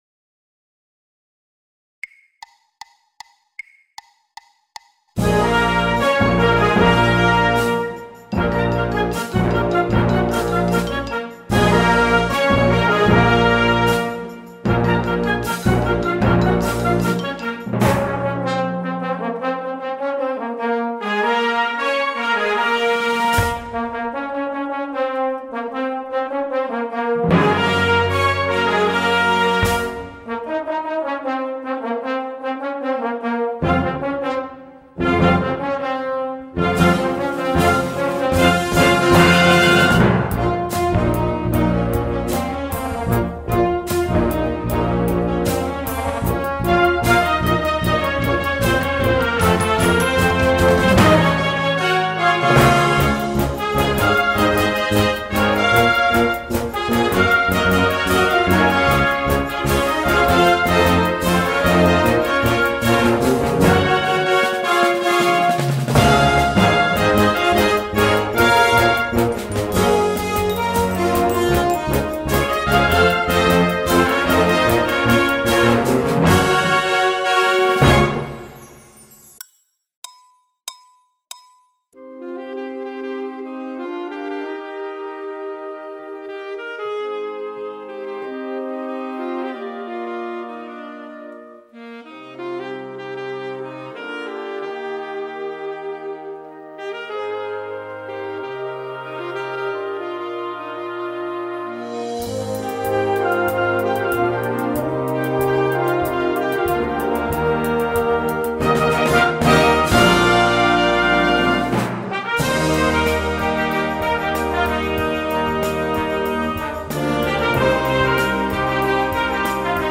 Gesamtaufnahme im Originaltempo (Anfangstempo 152bpm),
The Greatest Showman_Hannover-Version_152bpm.mp3